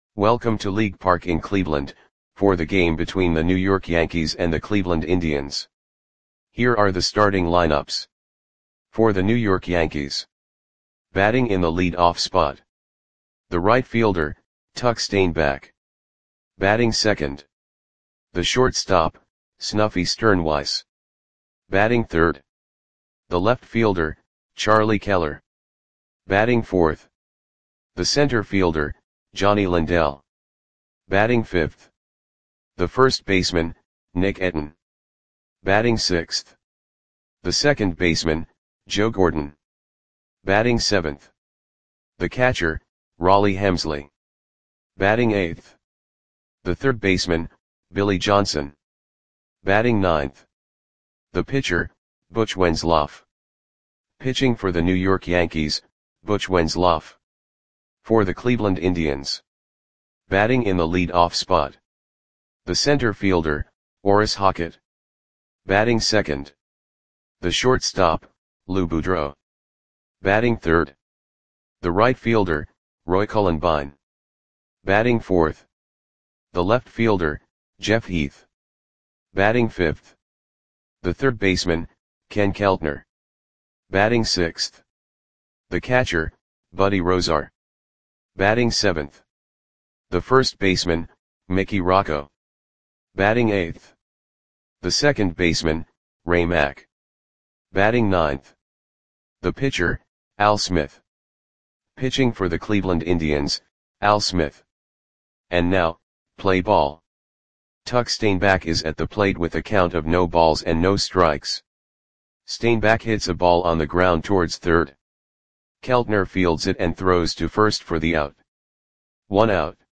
Audio Play-by-Play for Cleveland Indians on July 1, 1943